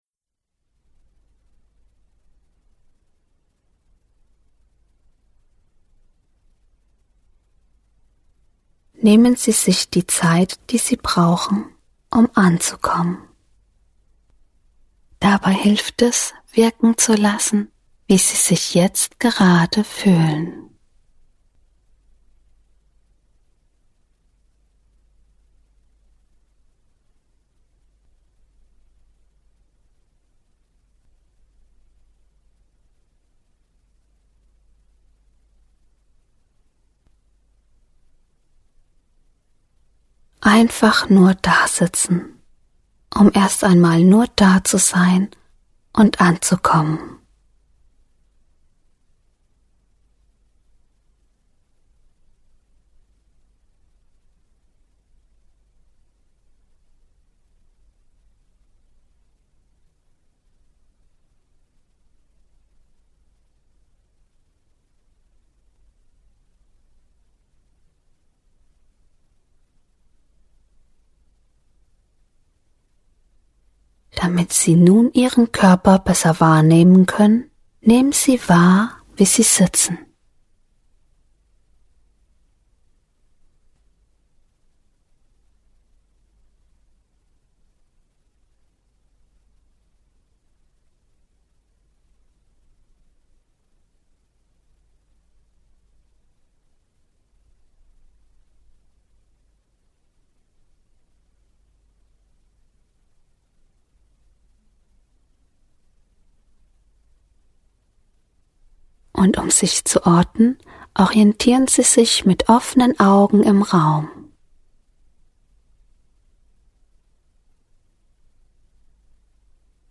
Hinweise: Die Meditation beginnt nach 8 Sekunden.